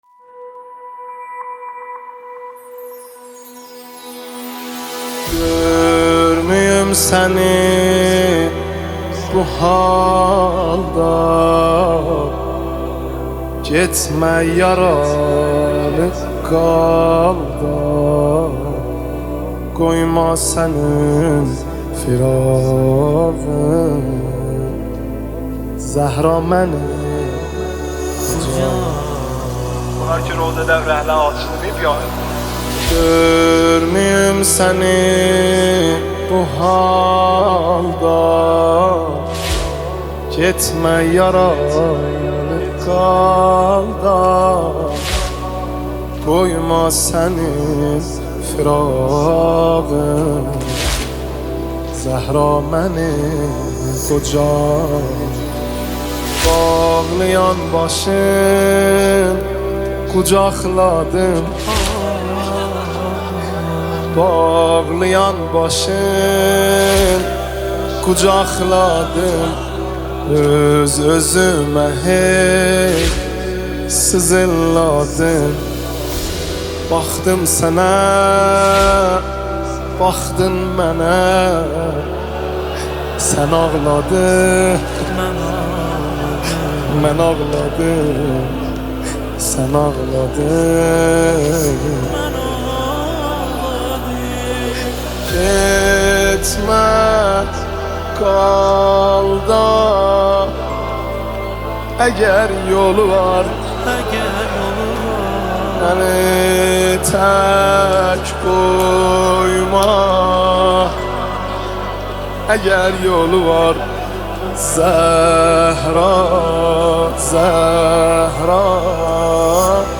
نماهنگ ترکی
مداحی ترکی